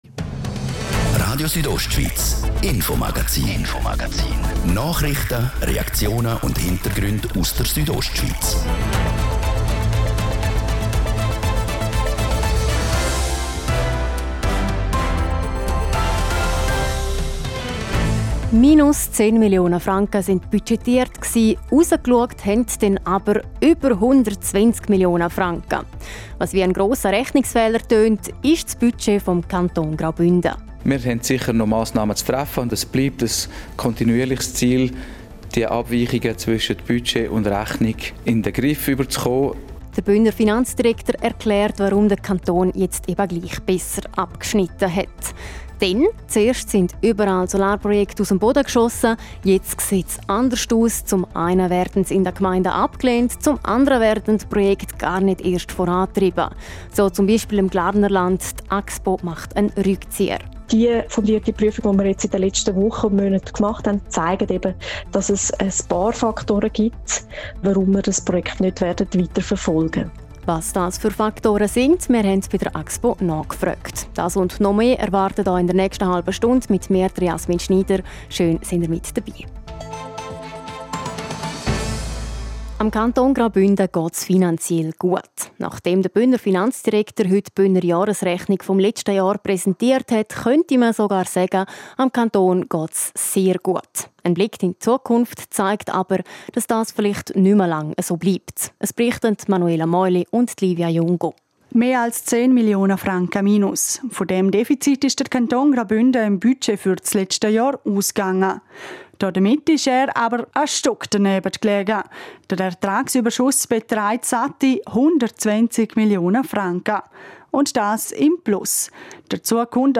18:00 Uhr News Merken Like 0 Dislike 0 Teilen Facebook E-Mail WhatsApp Link